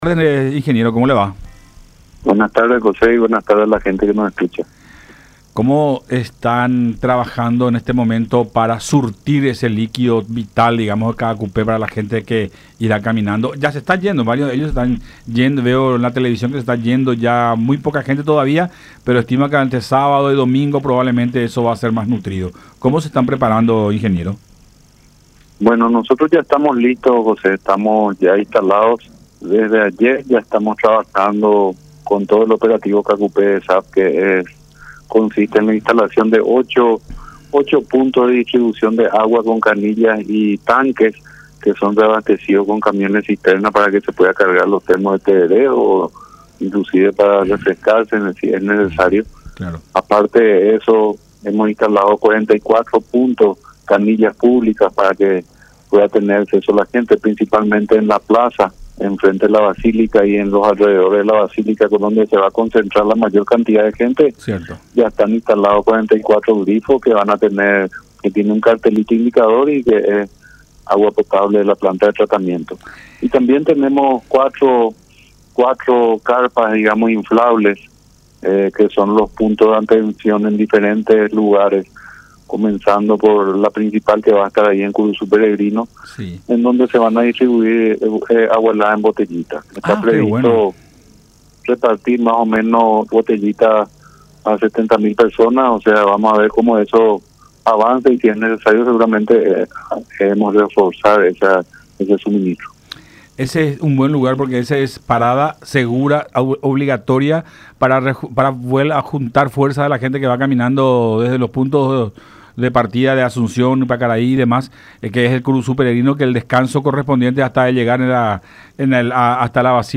Además, vamos a tener cuatro carpas como punto de atención, una de ellas como base en Kurusu Peregrino”, detalló Chase en diálogo con Buenas Tardes La Unión.